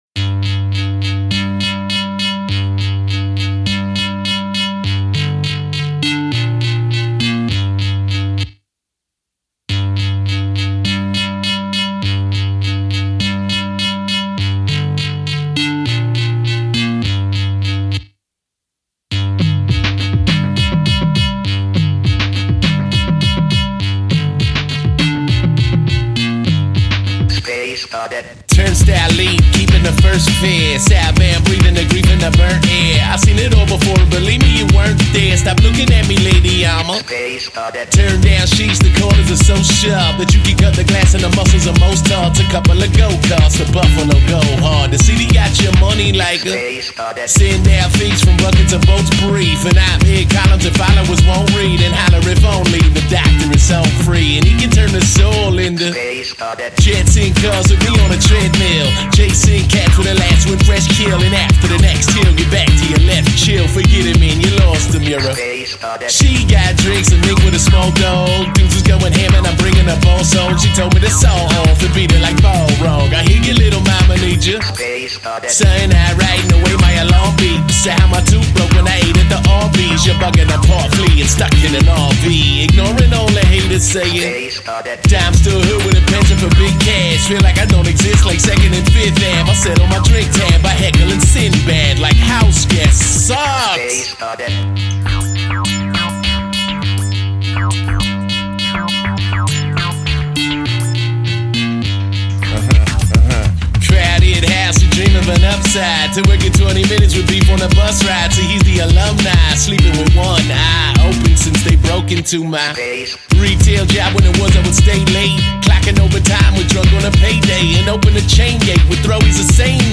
the game used that voice synth sample too.